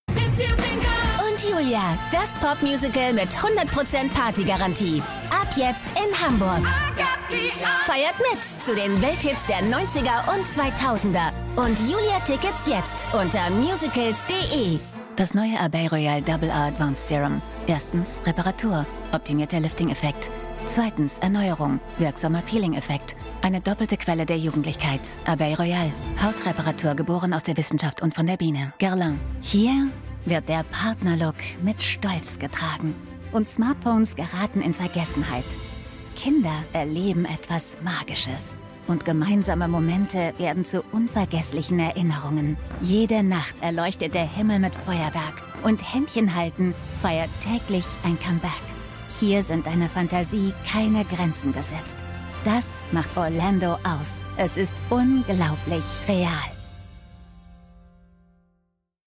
Female
Approachable, Assured, Authoritative, Bright, Character, Confident, Conversational, Cool, Corporate, Energetic, Engaging, Friendly, Natural, Posh, Reassuring, Smooth, Soft, Upbeat, Versatile, Warm
Microphone: Austrian Audio OC18